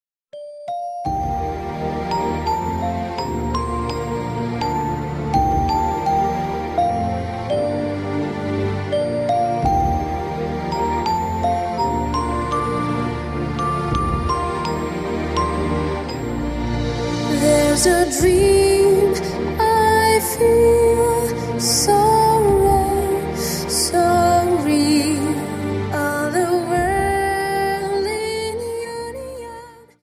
Dance: Slow Waltz Song